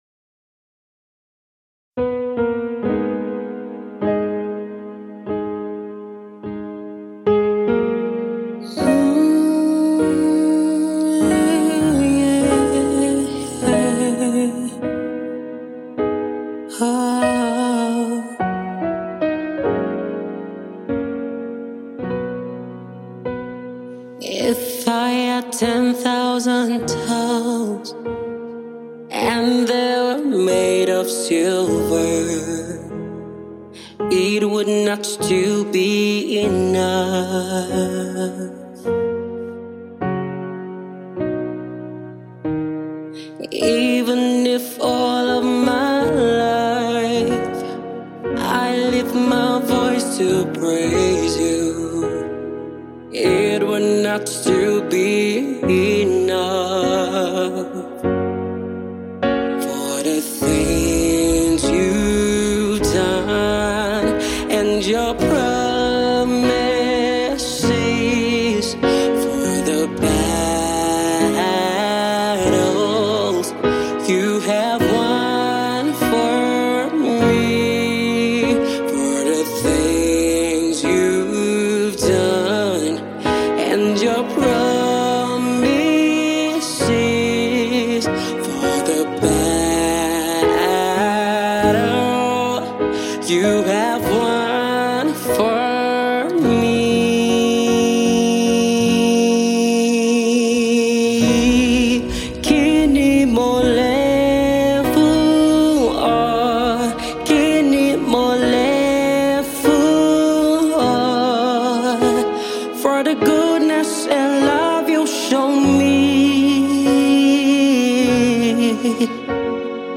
whose voice resonates so beautifully